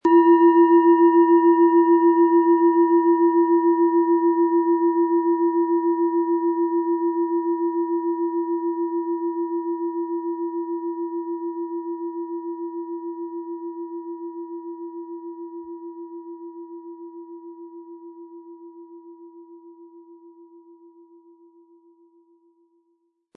Planetenton
Im Sound-Player - Jetzt reinhören können Sie den Original-Ton genau dieser Schale anhören.
SchalenformBihar
MaterialBronze